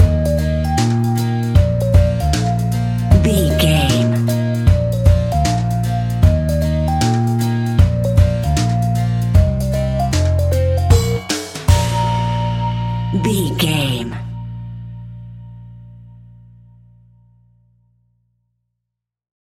royalty free music
Ionian/Major
B♭
childrens music
childlike
happy
kids piano